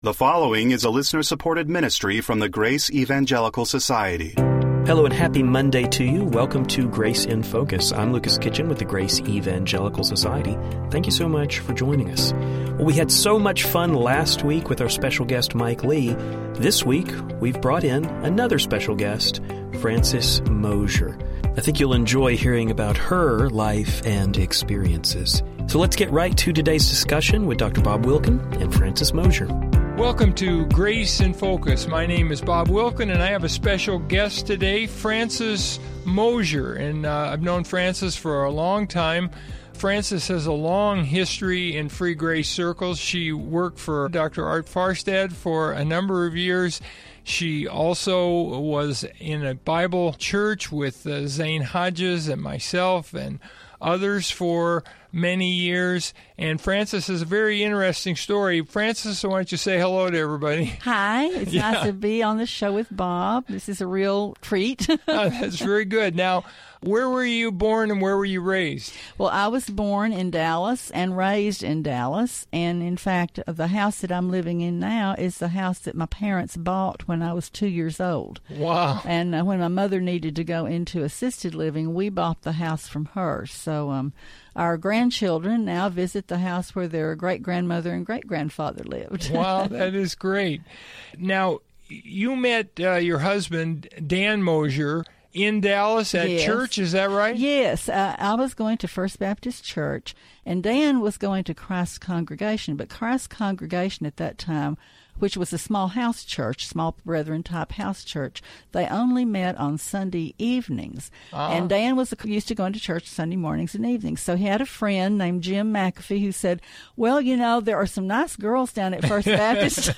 We hope you enjoy the conversation.